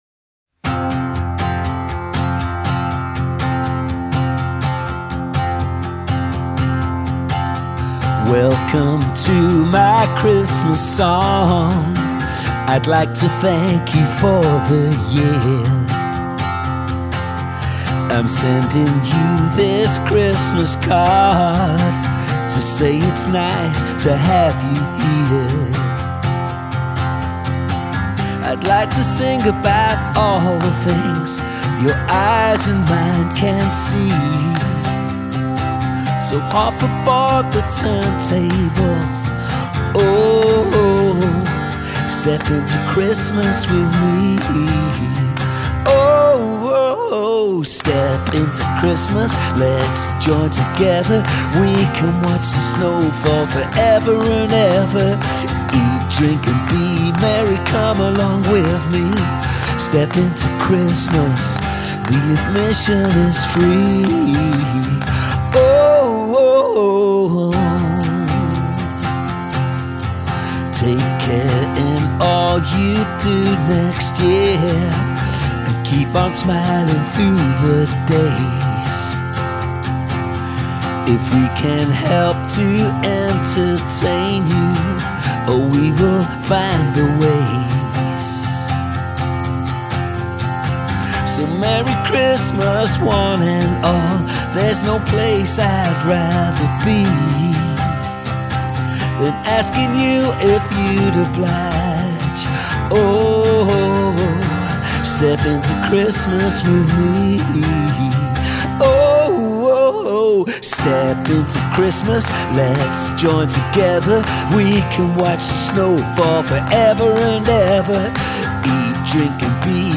2020 Acoustic recording
Vocals, Acoustic Guitar, Handclaps